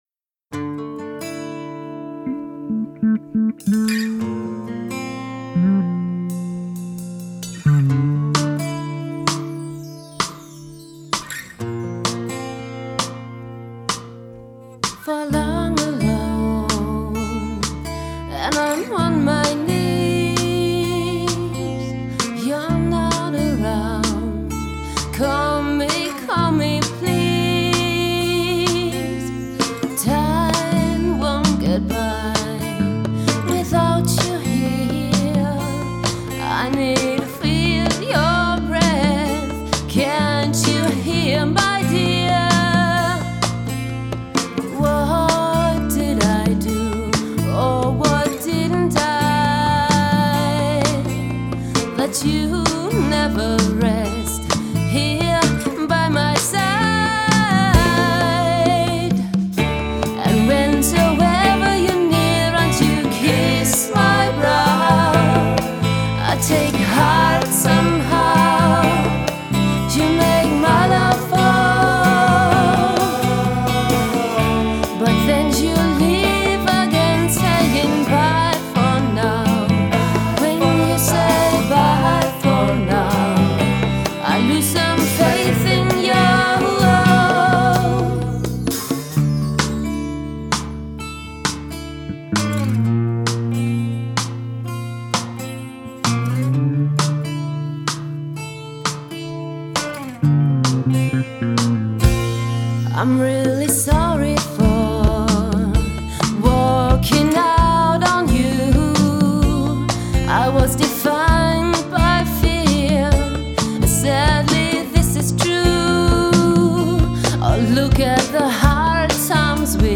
Ich habe früher hobbymäßig mal in Bands gesungen und gespielt; besonders in einer.
Manch eine Melodie, Text und Keyboardpart entsprangen meiner Feder.
Einer unserer ersten Songs, ein langsamerers, gefühlvolles Stück.